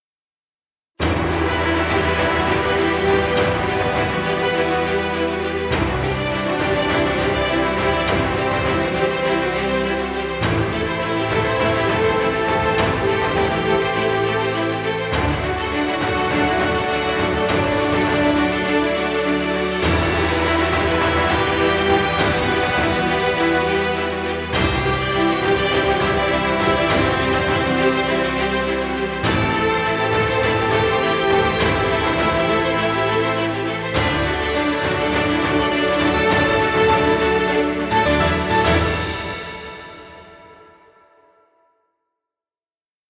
Epic music, exciting intro, or battle scenes.